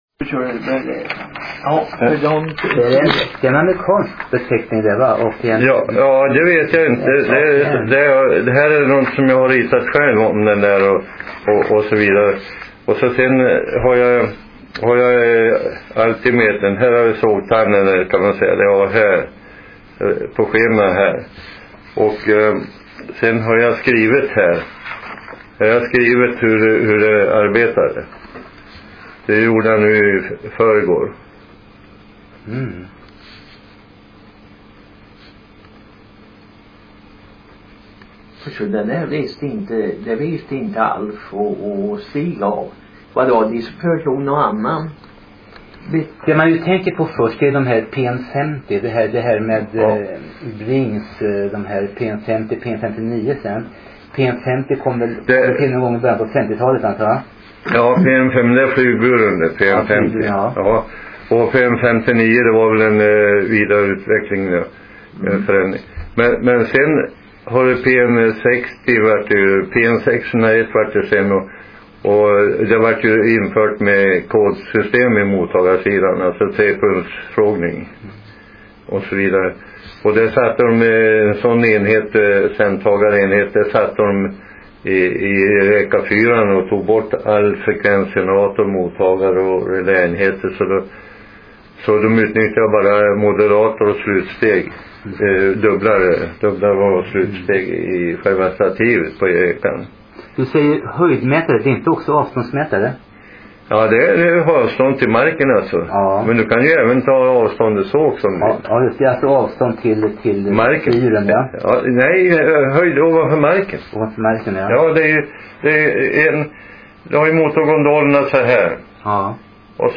Intervju